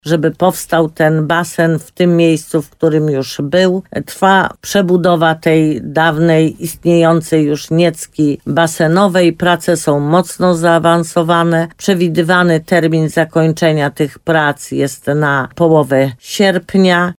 – Mamy tam plac budowy, więc ze względów bezpieczeństwa uruchomienie tej atrakcji nie jest możliwe – powiedziała burmistrz Limanowej, Jolanta Juszkiewicz.